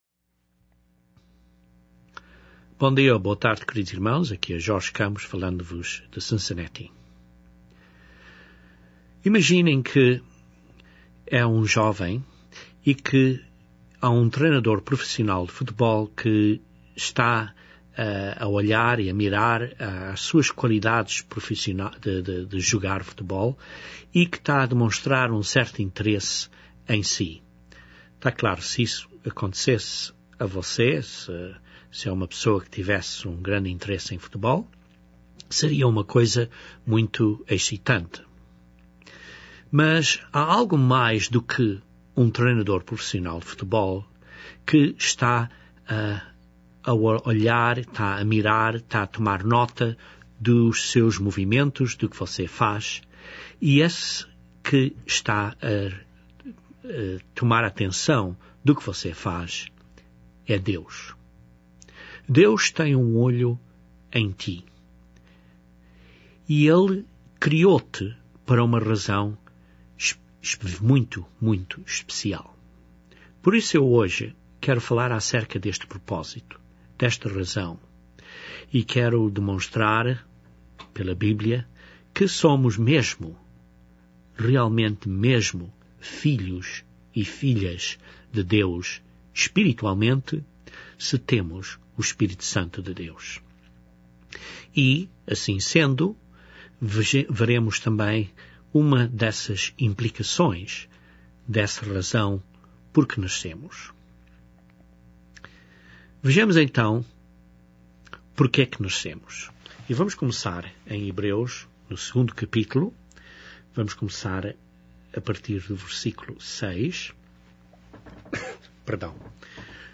Este sermão abre as escrituras que claramenete demonstram que nascemos para sermos filhos e filhas de Deus.